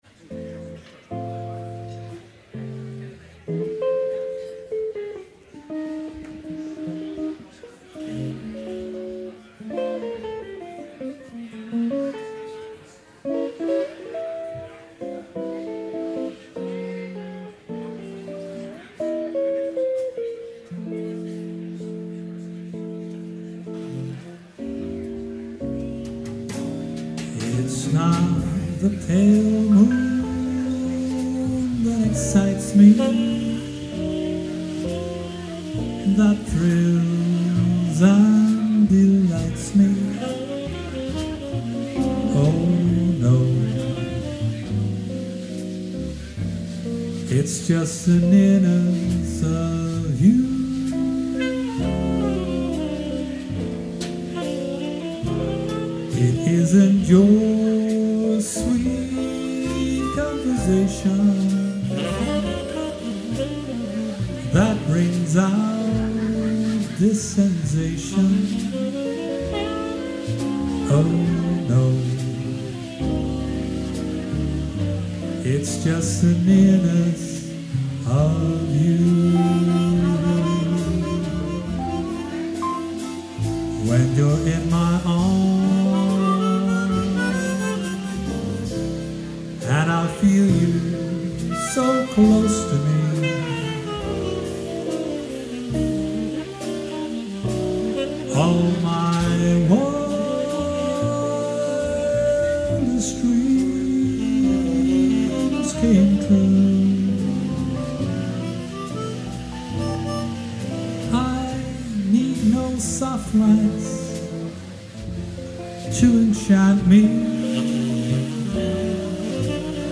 Jazz
Zang
Mondharmonica
Tenorsax
Altsax
Gitaar
Piano
Drums